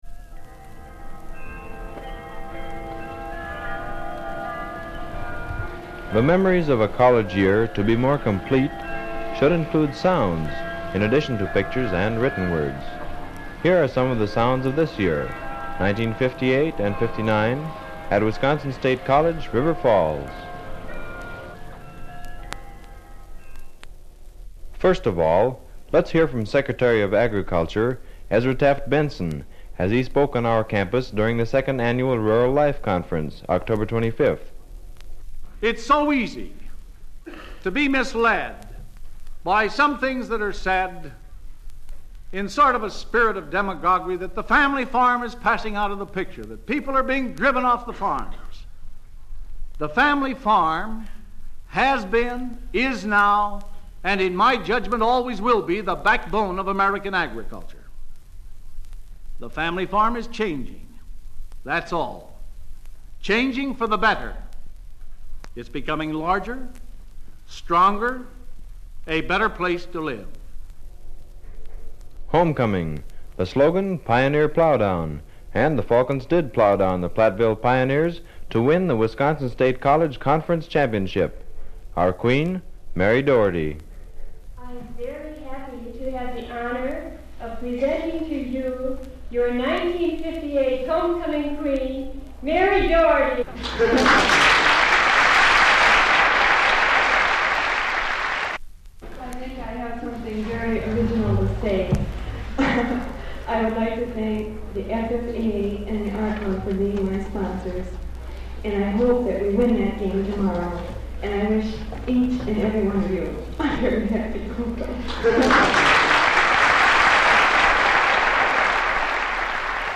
The 1959 Meletean includes 160 pages plus Sound Sketches of 1959 - a 45 vinyl record (10 minutes) that has been converted to both MP3 and WAV audio file formats.